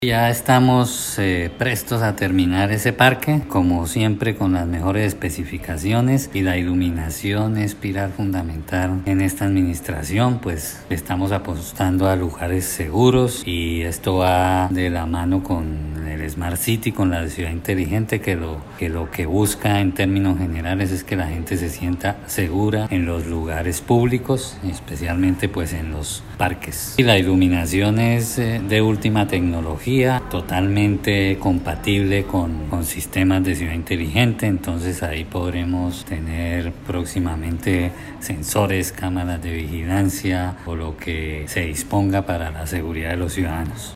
Descargue audio: Iván Vargas, secretario de Infraestructura